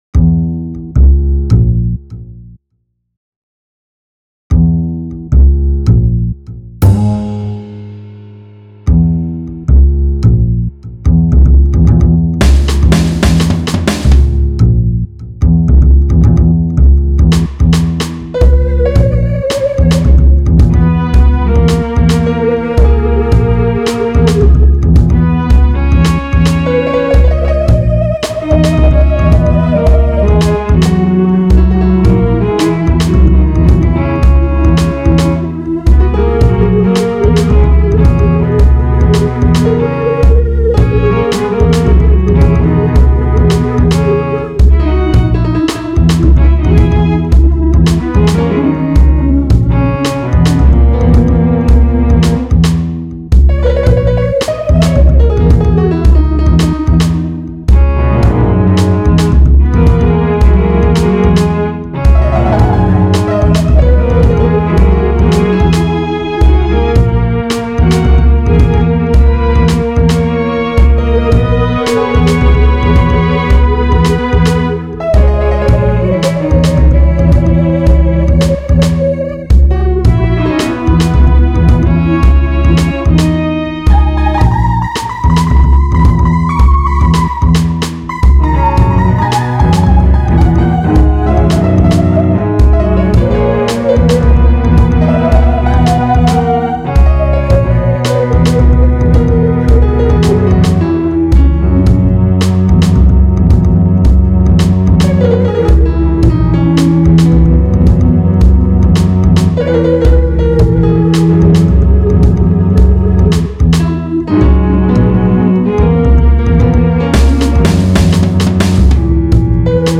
jazz , rhythmic